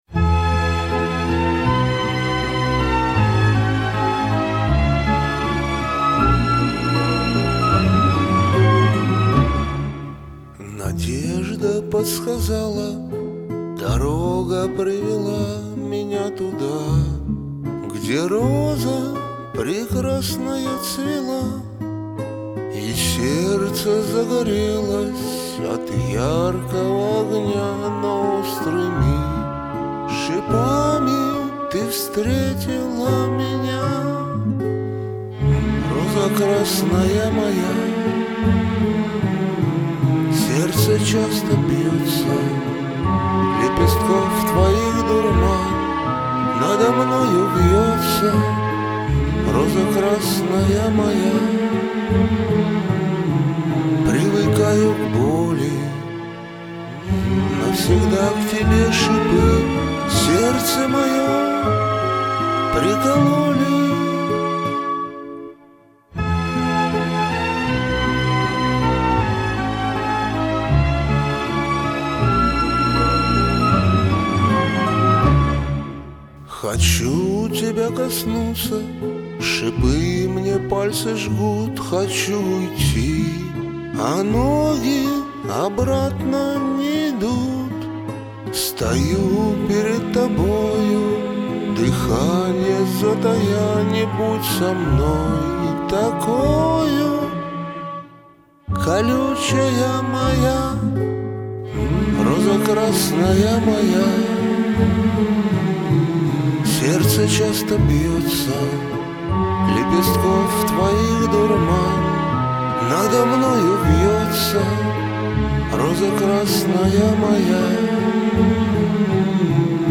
и звук чудесный..слышу каждую нотку..